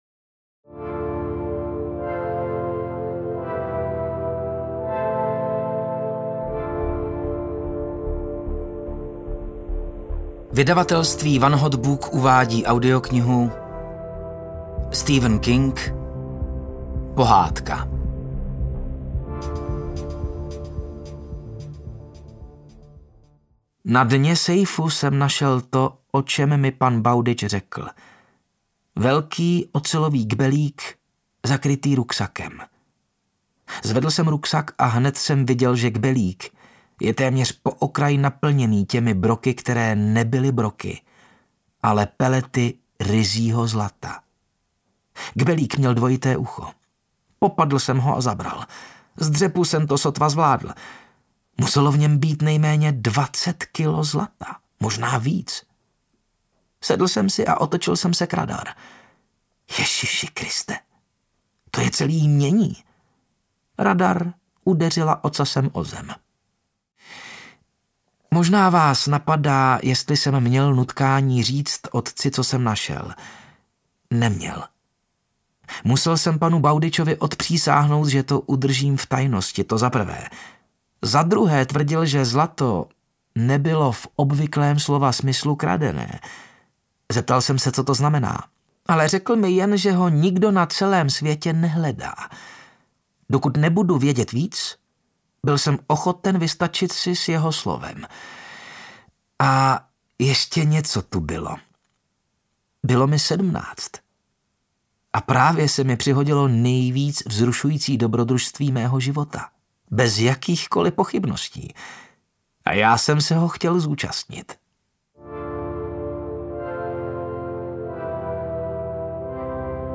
Pohádka audiokniha
Ukázka z knihy